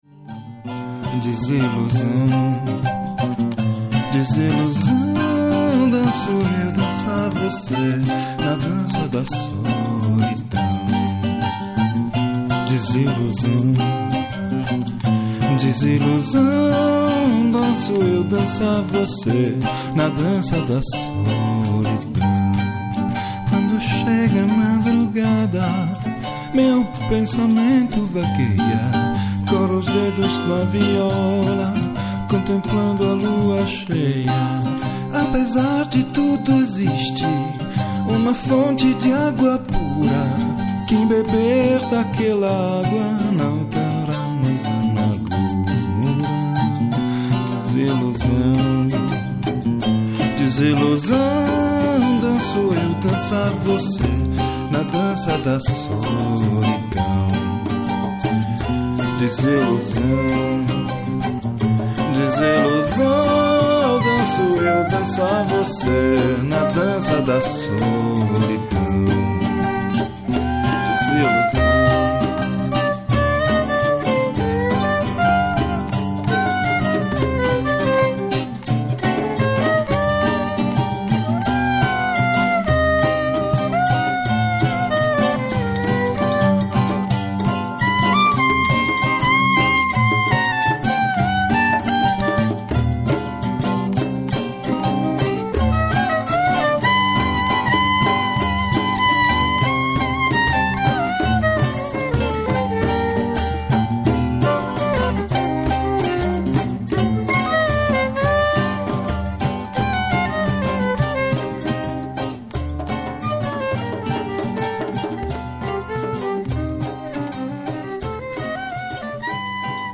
violin, vocals, viola, mandolin, guitars, percussion
in Genova, Italy, on September 2006